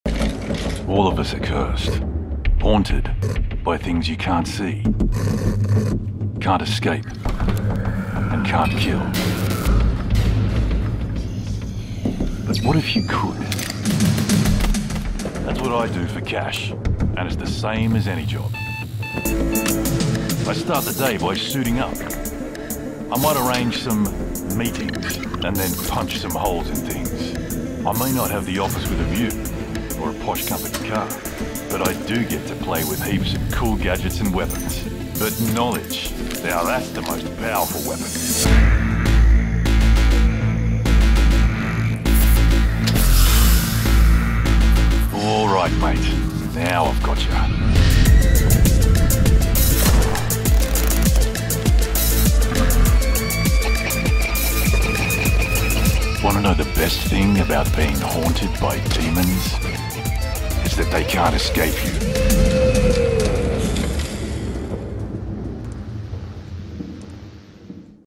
Inglês (australiano)
Videogames
BarítonoGravesContraltoProfundoBaixo